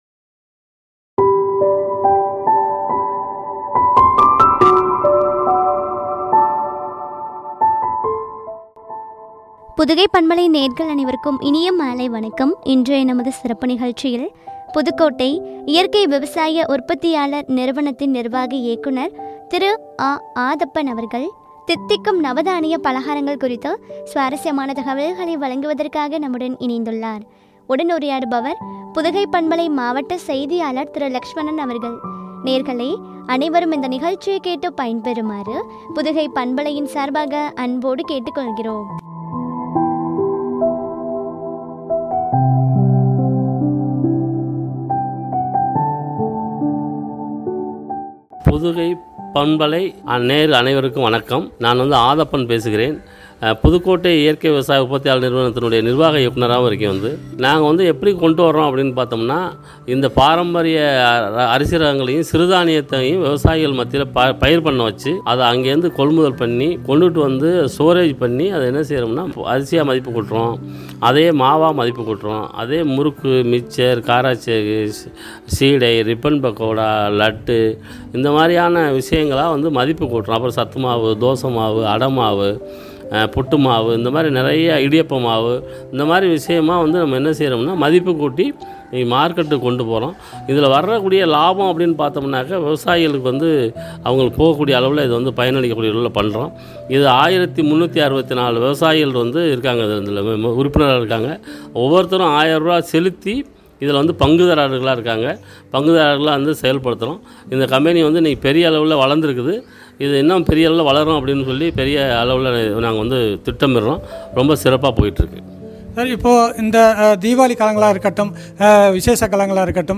” தித்திக்கும் நவதானிய பலகாரங்கள்” குறித்து வழங்கிய உரையாடல்.